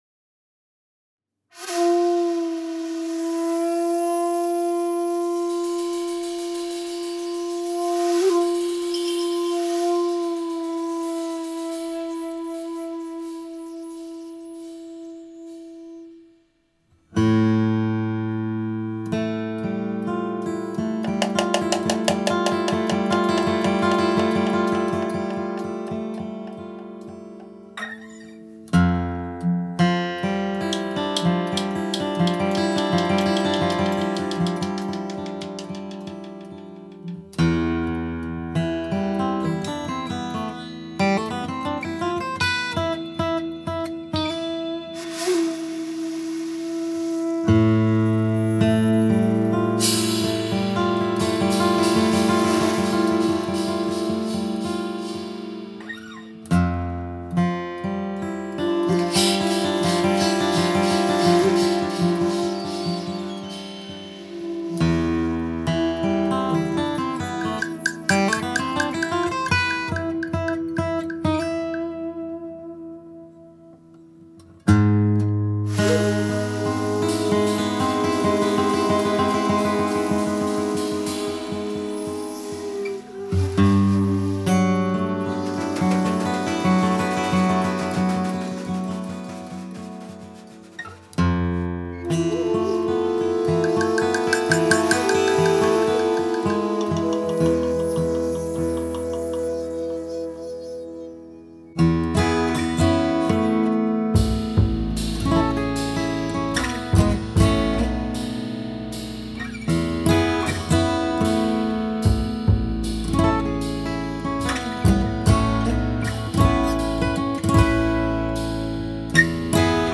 Guitars, Shakuhachi
Upright Bass
Percussions